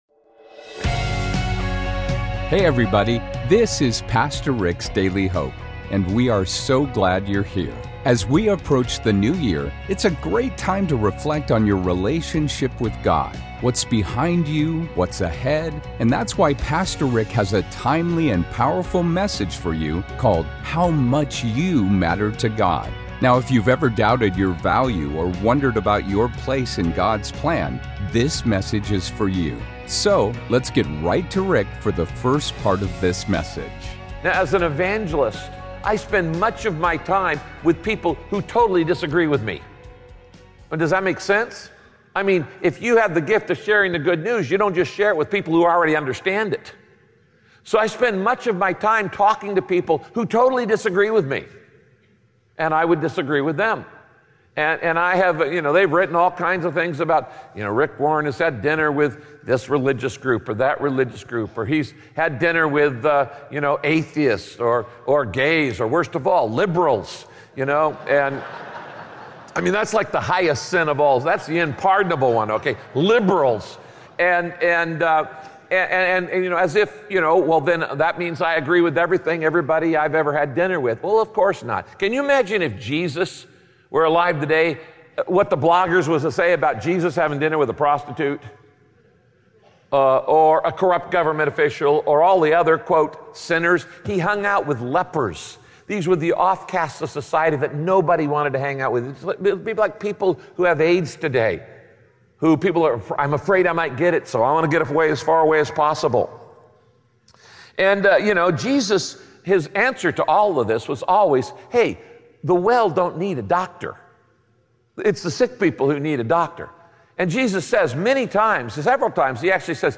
Our deepest needs in life are to feel secure and valued—to know our lives matter and to make a difference. In this message, Pastor Rick shares how faulty it is…